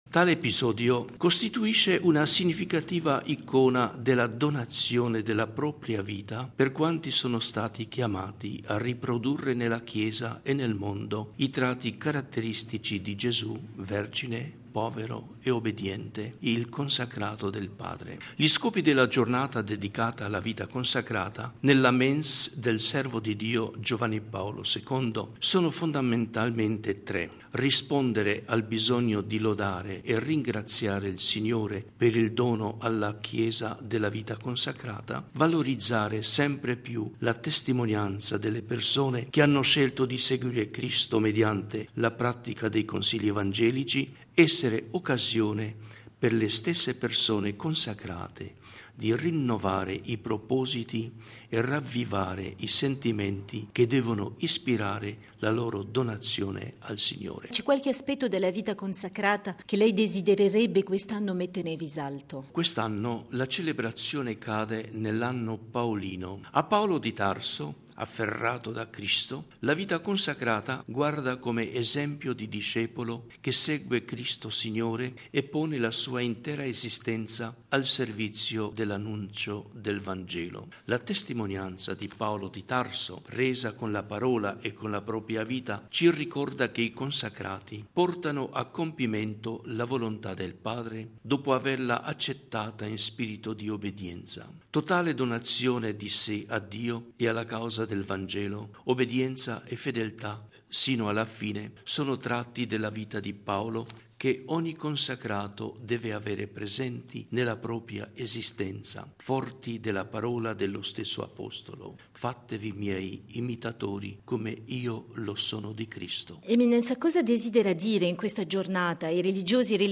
Alle 18, l'incontro del Papa con i religiosi e le religiose per la 13.ma Giornata della vita consacrata. Intervista al cardinale Franc Rodé